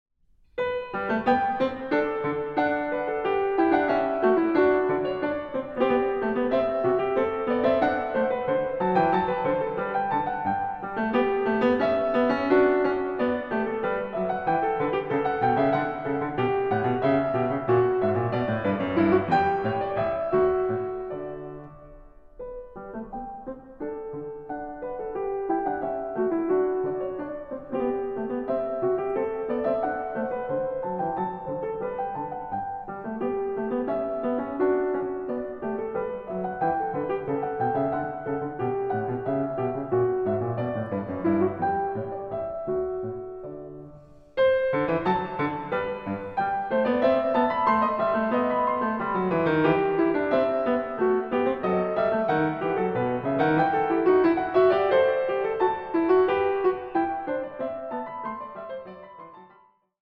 a 1 Clav.